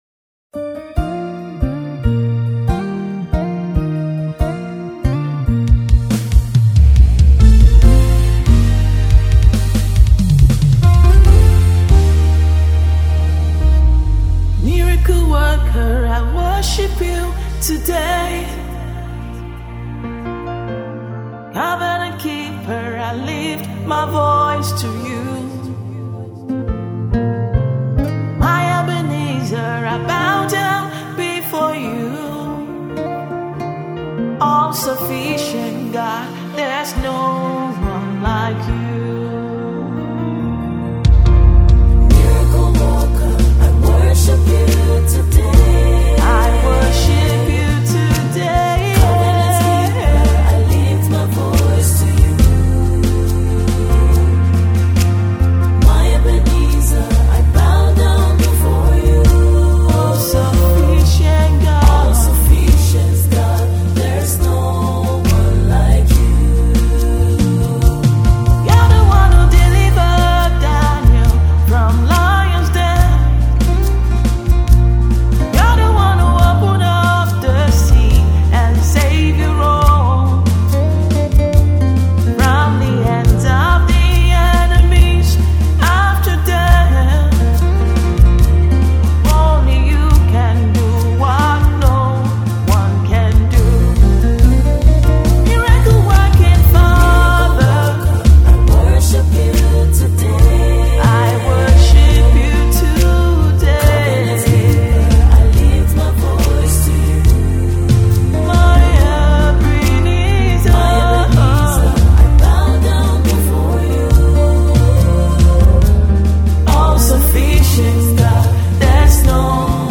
Prolific Nigerian Gospel Music Minister